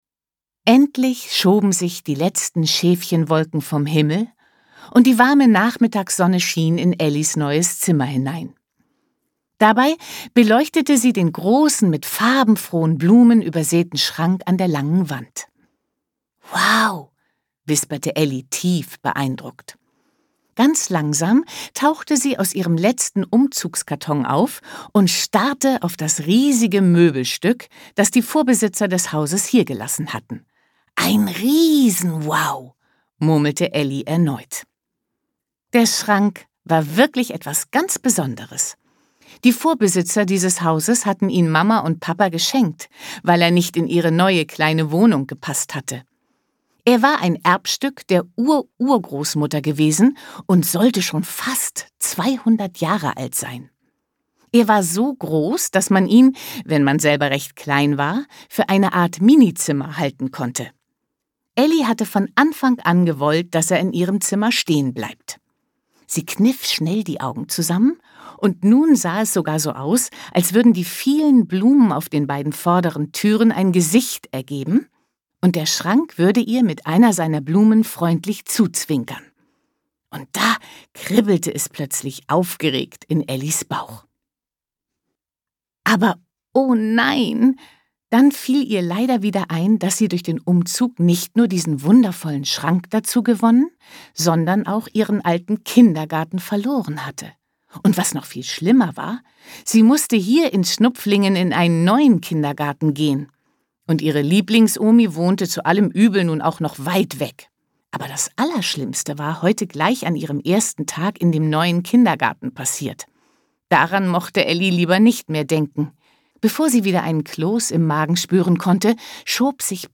Hörbuch für Kinder